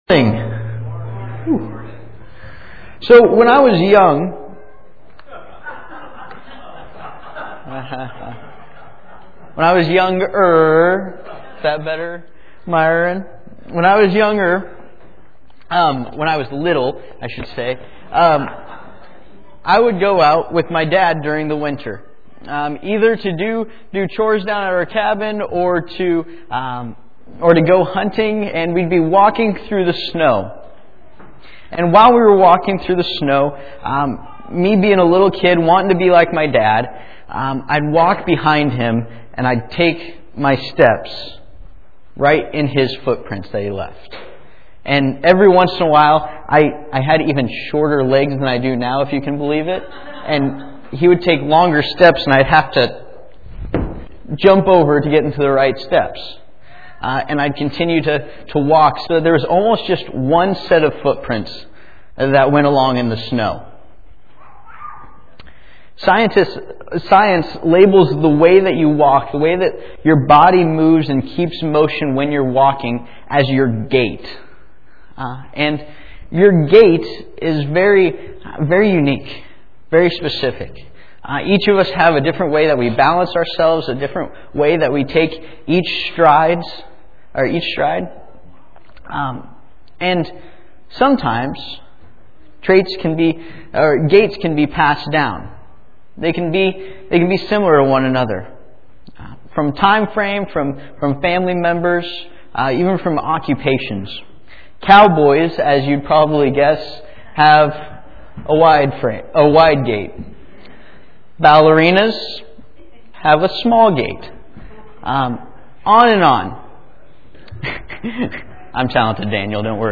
Passage: John 1:1-18 Service Type: Sunday Morning